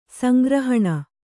♪ sangrahaṇa